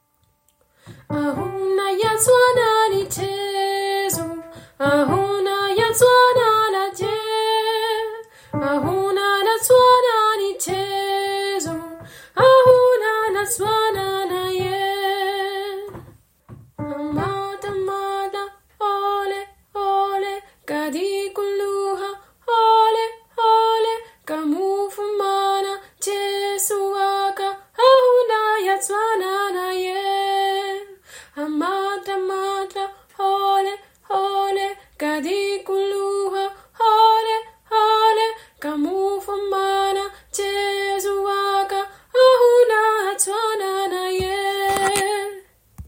Soprane